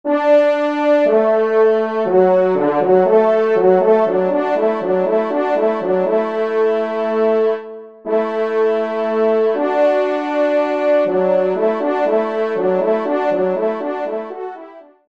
Pupitre 2°Trompe